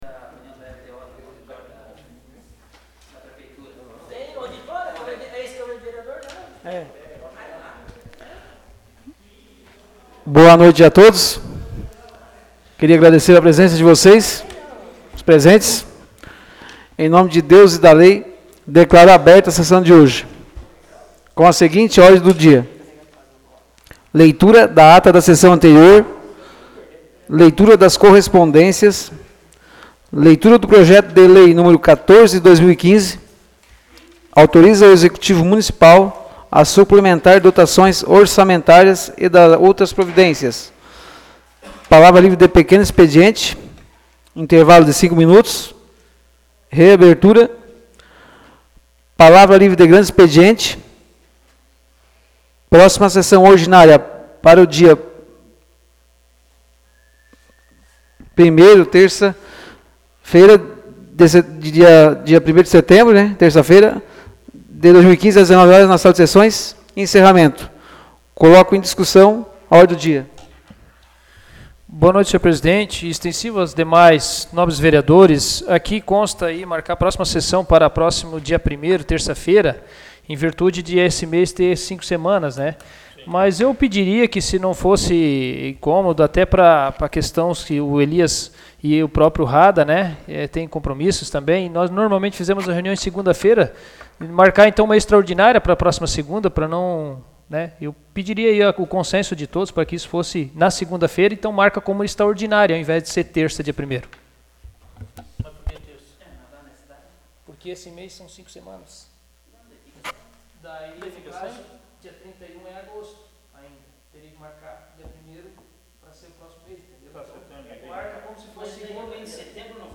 Áudio da Sessão Ordinária de 24 de agosto de 2015.